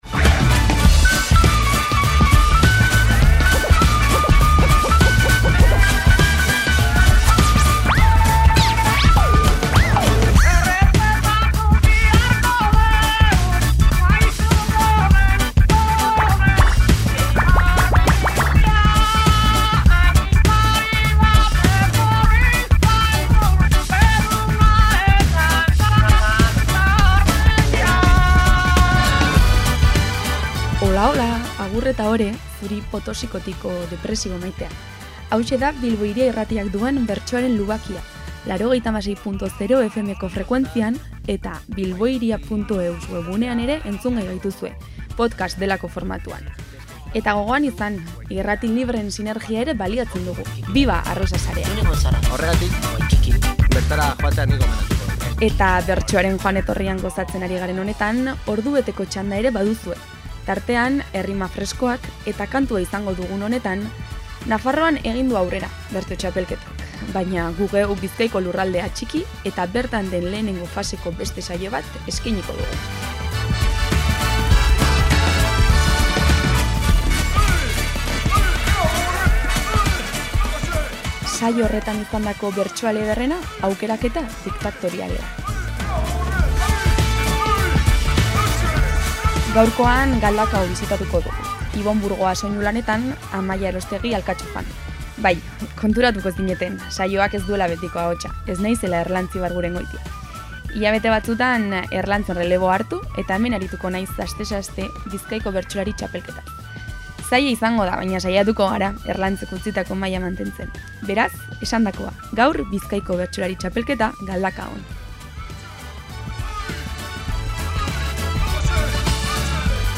Bizkaiko Bertsolari Txapelketako laugarren saioa izan da Galdakaon, eta bertsoen aukeraketa diktatoriala duzue entzungai gaurko saioan.